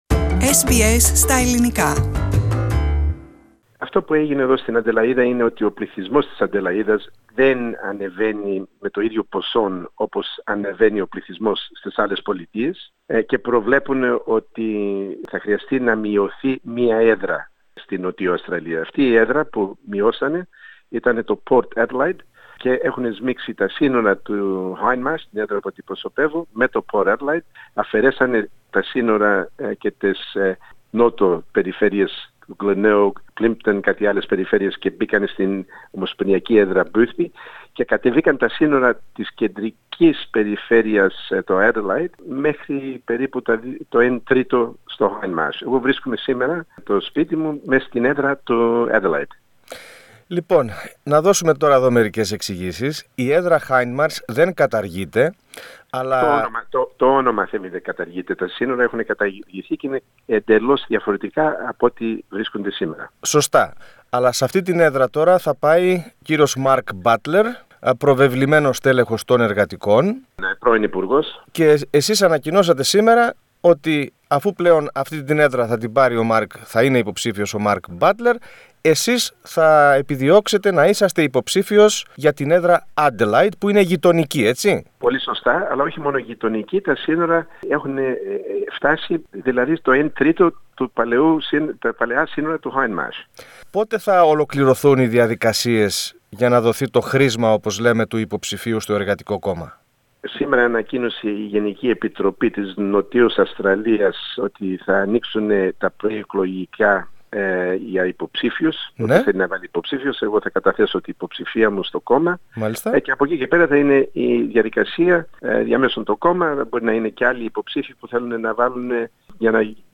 Greek-Australian politician, Steve Georganas, will seek to be the candidate of ALP in the seat of Adelaide after the recent distribution of his current seat, Hindmarsh. He talks to SBS Greek.